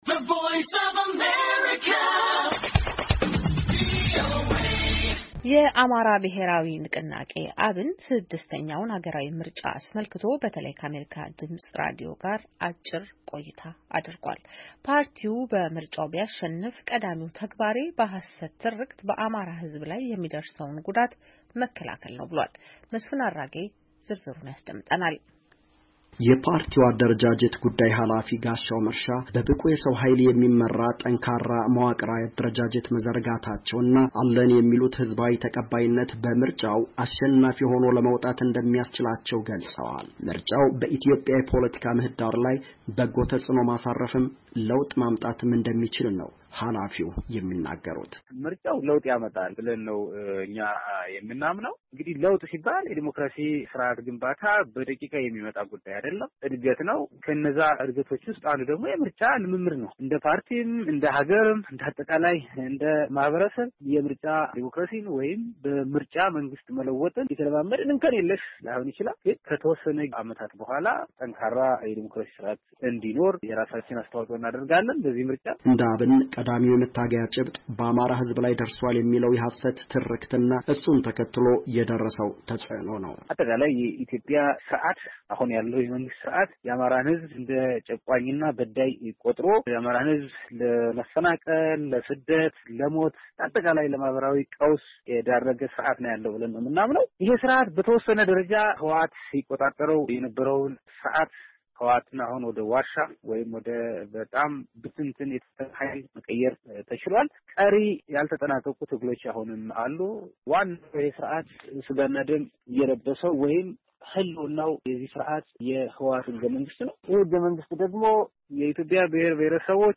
የአማራ ብሔራዊ ንቅናቄ /አብን/ ስድስተኛውን ሀገራዊ ምርጫ አስመልክቶ በተለይ ከአሜሪካ ድምፅ ራዲዮ ጋር አጭር ቆይታ አድርጓል፡፡ ፓርቲው በምርጫው ቢያሸንፍ ቀዳሚው ተግባሬ በሃሰት ትርክት በአማራ ህዝብ ላይ ሚደርሰውን ጉዳት መከላከል ነው ብሏል፡፡